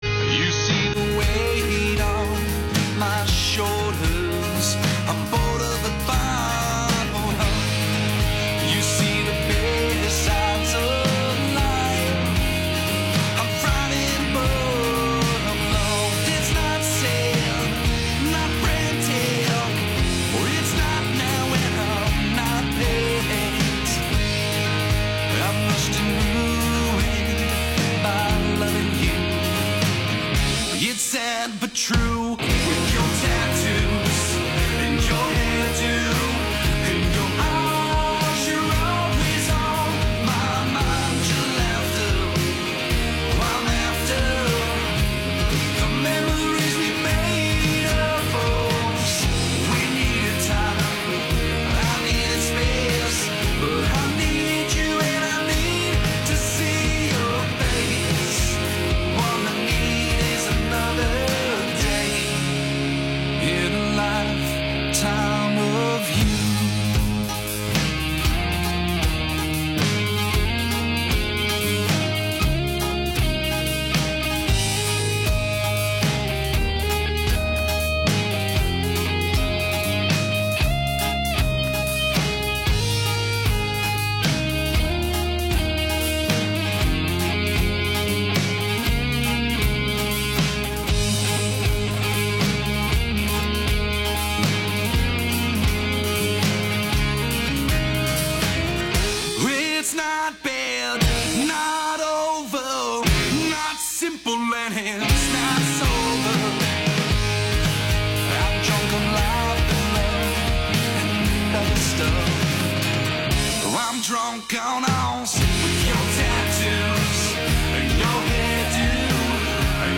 We’ll hear about his solo career as well as his other bands and some incredible names he has played with. We are also going to do a replay of last weeks Called To Be Saints segments as a technical glitch prevented their songs from being featured.